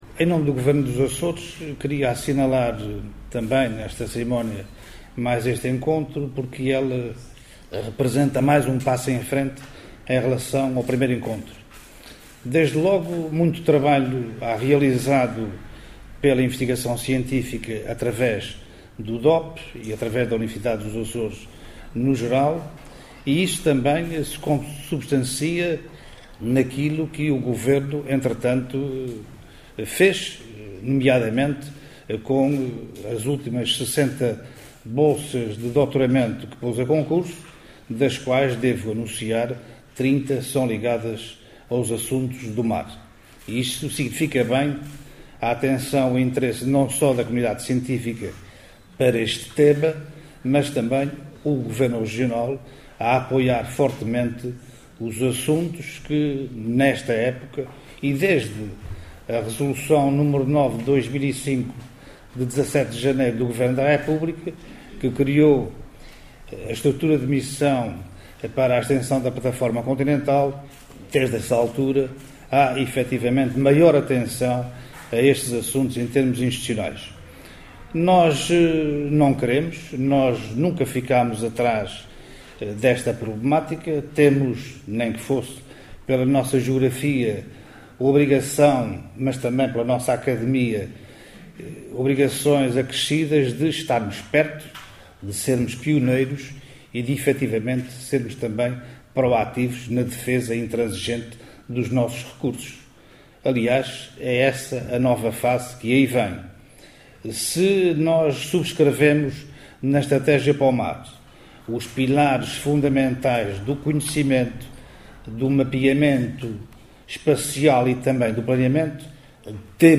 José Contente, que presidiu à sessão de abertura do seminário “Conhecer o Mar dos Açores”, que decorre hoje e amanhã na cidade da Horta, considerou que se deve manter a aposta nos três pilares fundamentais da promoção e defesa pró-ativa dos interesses regionais ao nível do mar: o conhecimento, o planeamento e ordenamento/mapeamento espaciais.